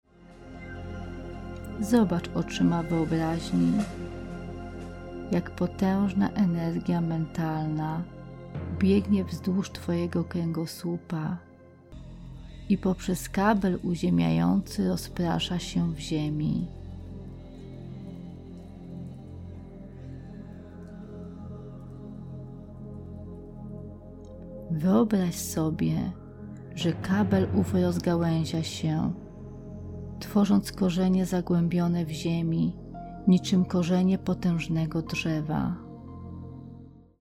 Medytacja uziemiająca i transofrmująca.